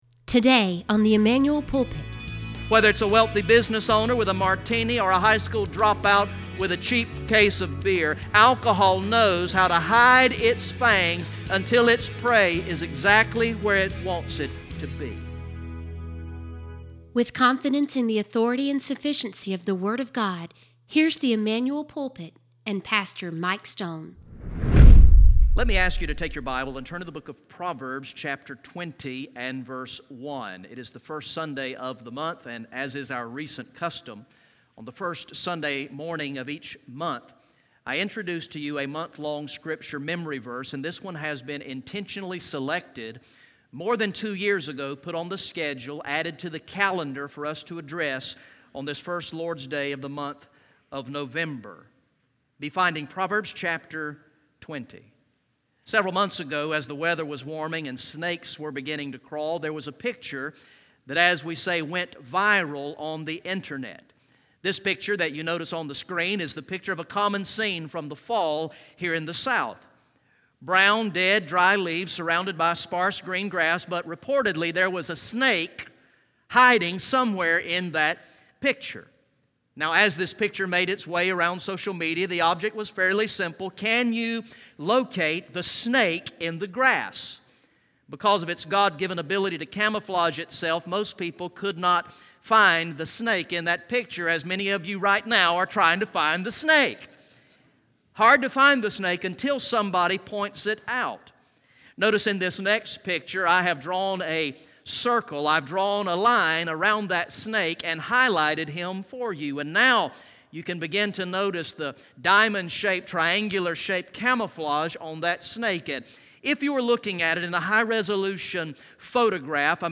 From the morning worship service on Sunday, November 5, 2017